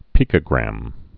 (pēkə-grăm, pī-)